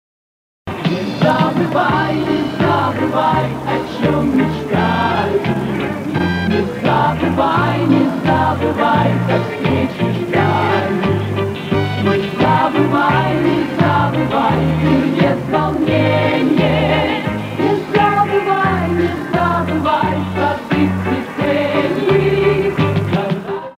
Вот фрагмент этой песни.
Очень мощная духовая секция.